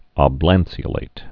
ob·lan·ce·o·late
(ŏb-lănsē-ə-lāt)